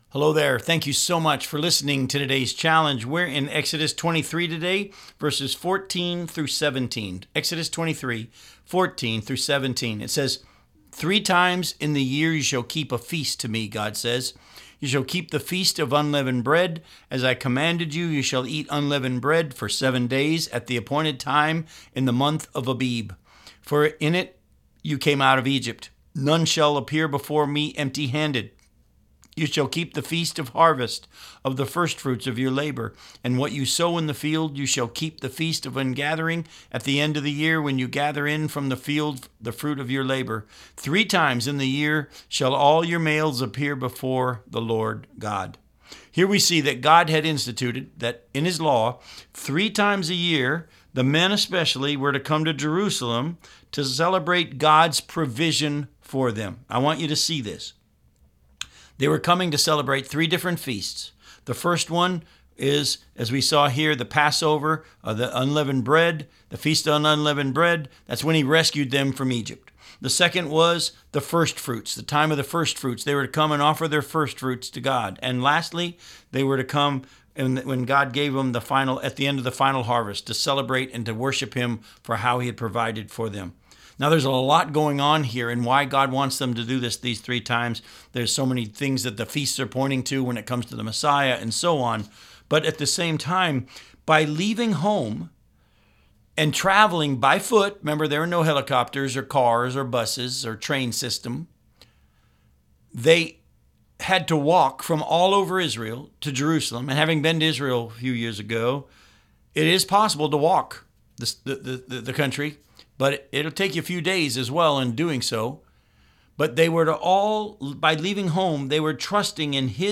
Challenge for Today Radio Program